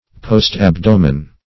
Search Result for " post-abdomen" : The Collaborative International Dictionary of English v.0.48: Post-abdomen \Post`-ab*do"men\, n. [Pref. post- + abdomen.]
post-abdomen.mp3